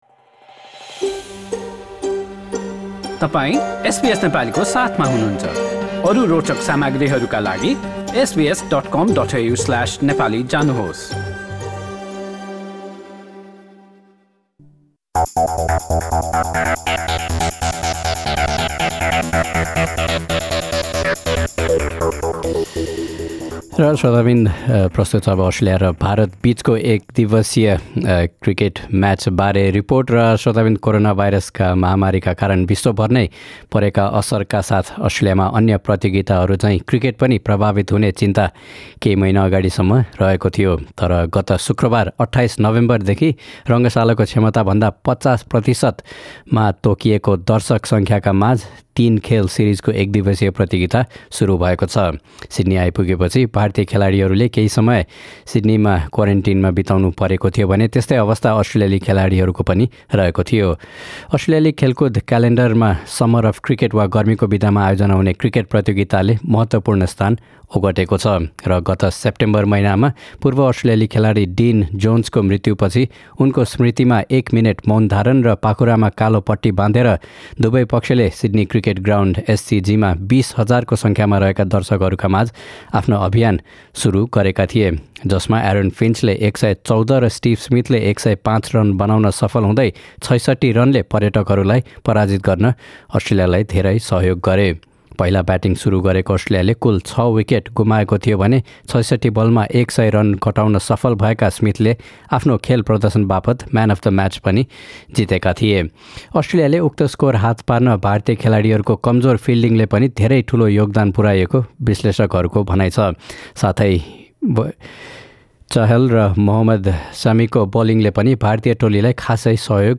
यसबारे थप रिपोर्ट माथि रहेको अडियो प्लेयरमा सुन्नुहोस्।